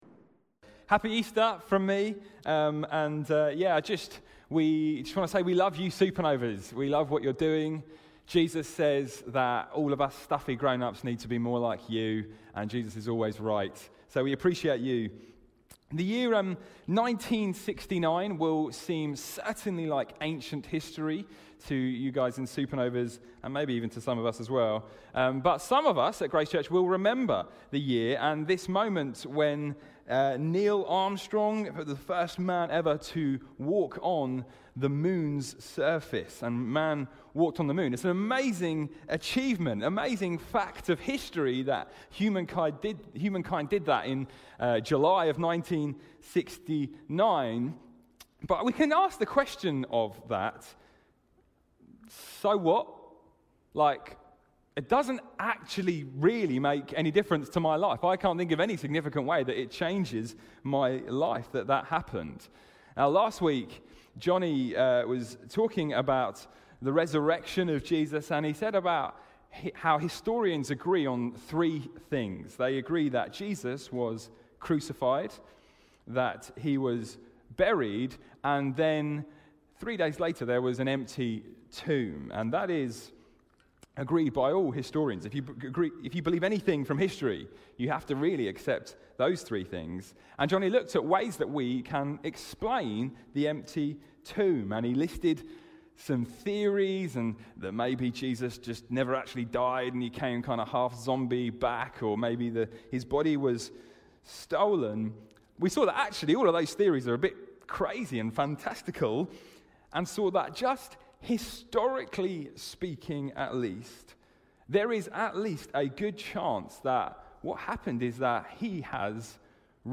Series: Miscellaneous Sermons 2021